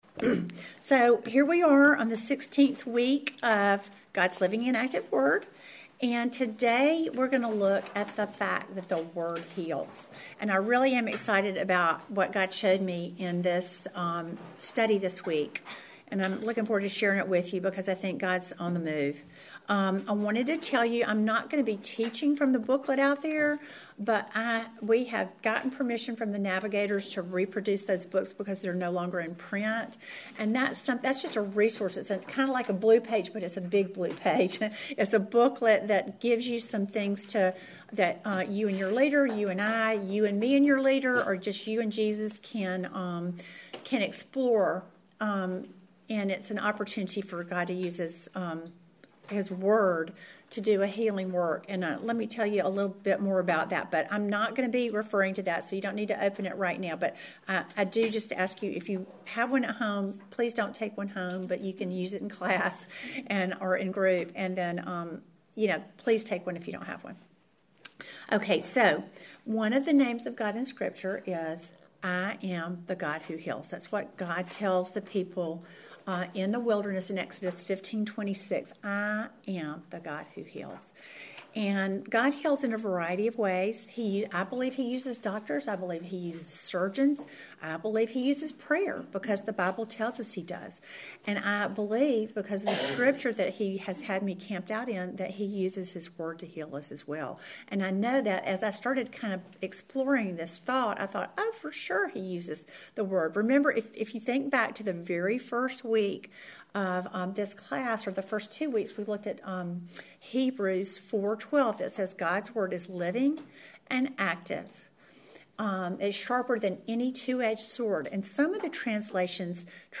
Welcome to the sixteenth lesson in our series GOD’S LIVING AND ACTIVE WORD!